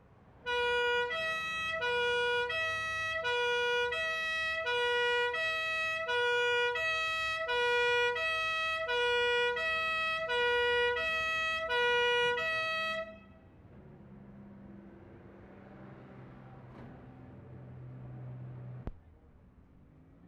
Amsterdam, Holland April 24/75
FIREHALL, SIREN (old model)
7. Taped inside, Interval P4, old model.